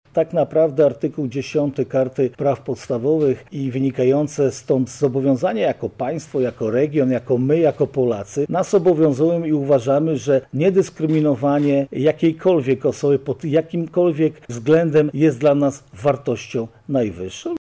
• mówi marszałek województwa lubelskiego Jarosław Stawiarski.